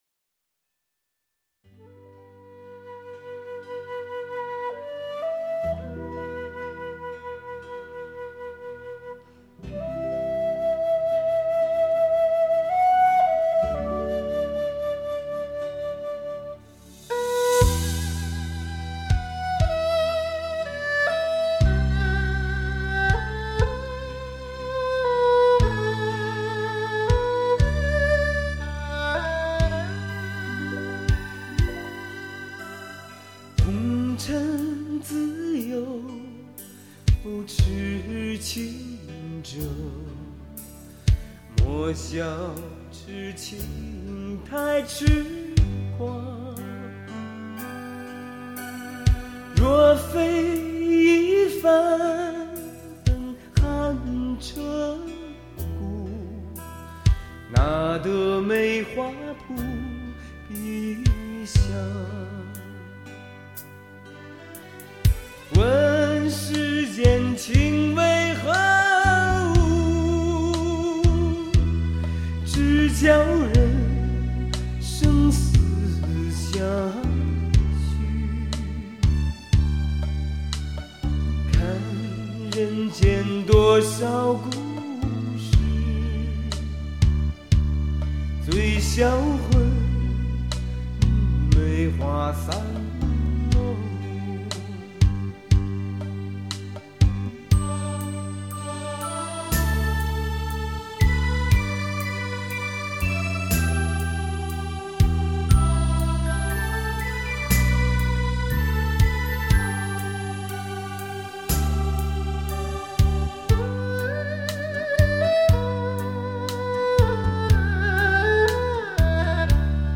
柔情之夜 带唱舞曲
慢四步